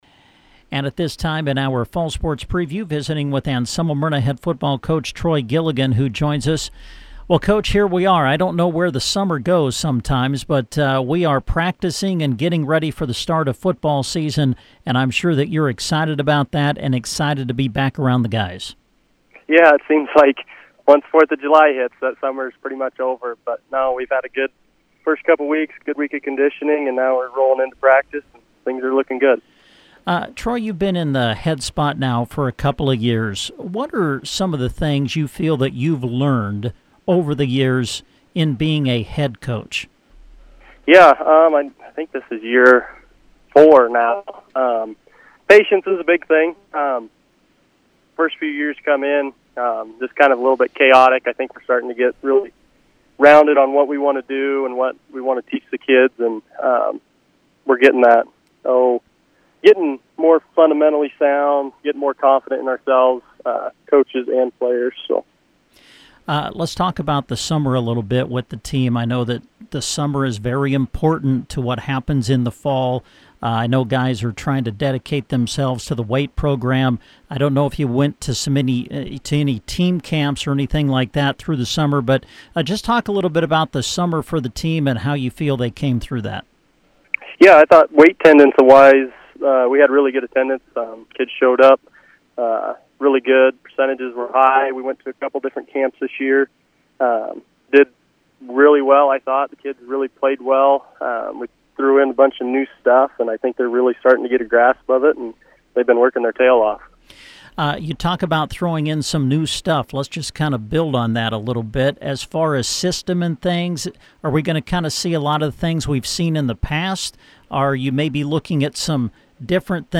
Anselmo-Merna Football Preview – Interview